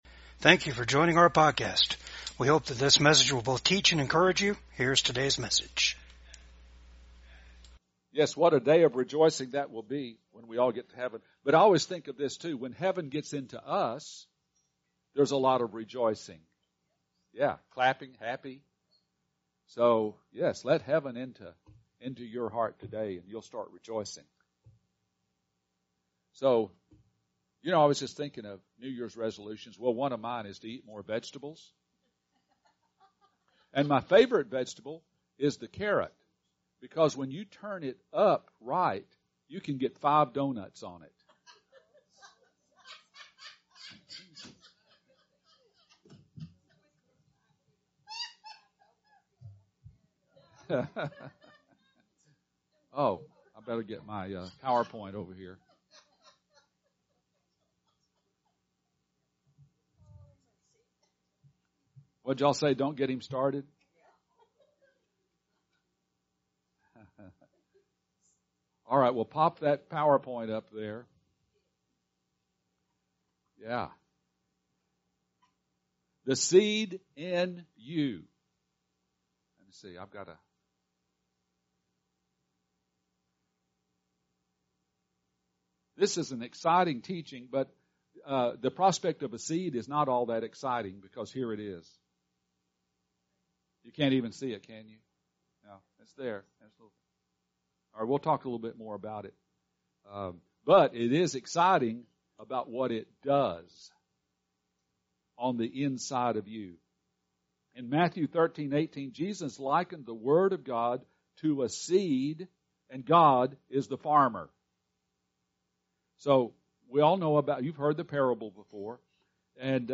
VCAG WEDNESDAY SERVICE